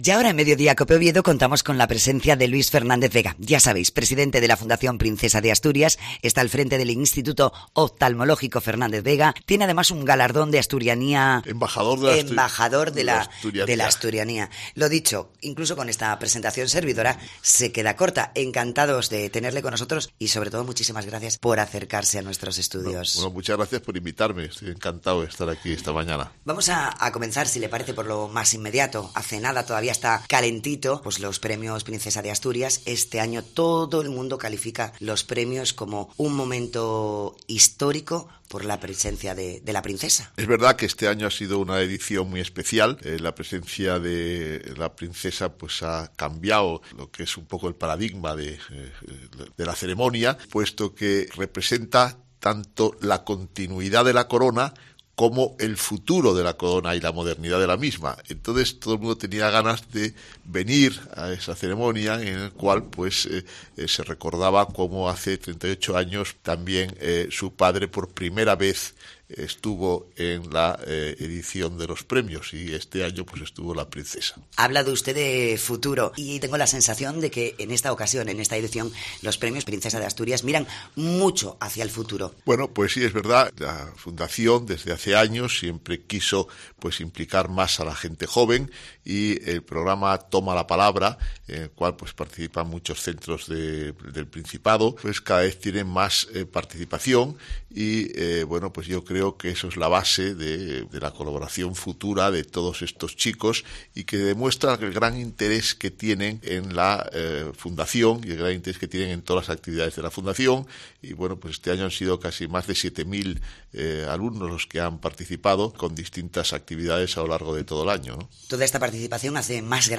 Su director médico, Luis Fernández-Vega, nos habla en Cope, de algunos de esos avances y tratamientos únicos en el país.